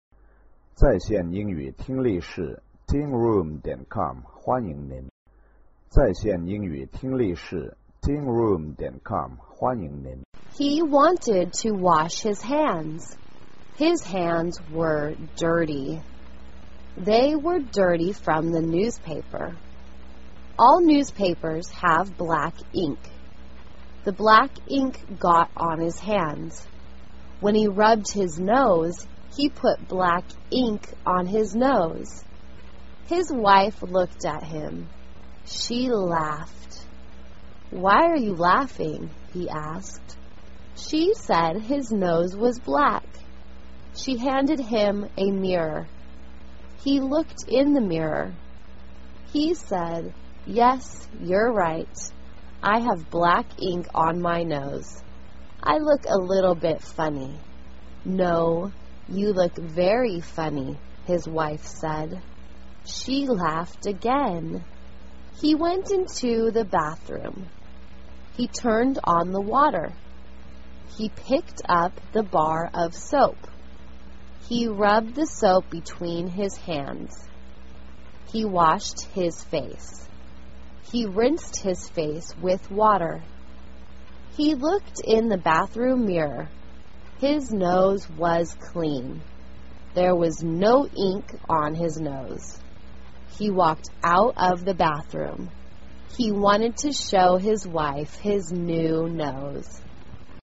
简单慢速英语阅读:Wash Your Nose 听力文件下载—在线英语听力室